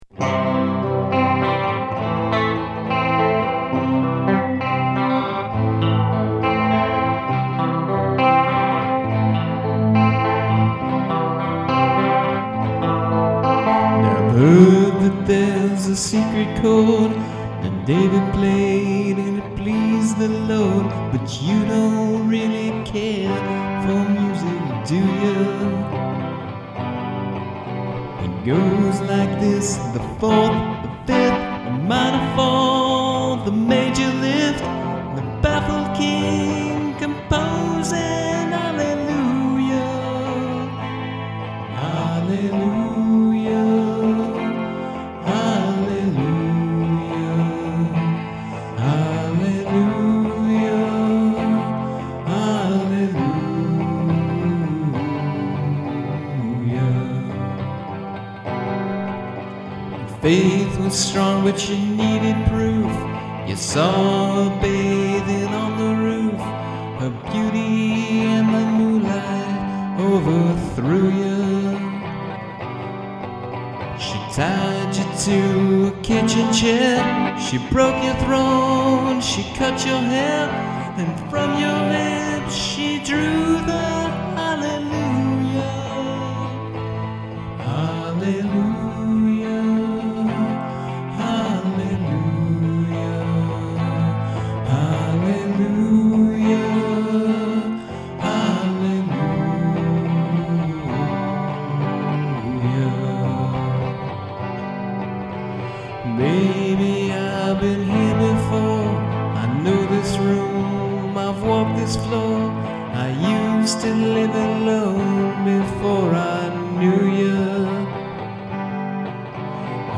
This was an experiment w/my home pc recording software.